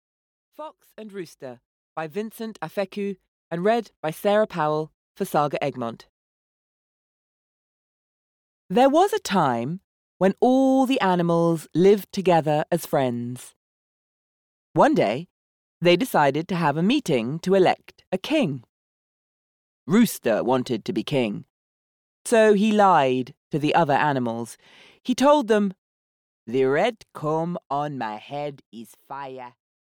Fox and Rooster (EN) audiokniha
Ukázka z knihy